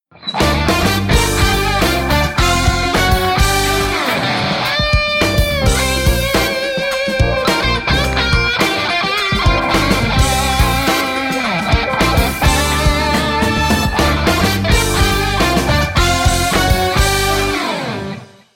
Мелодии на звонок
Нарезка на смс или будильник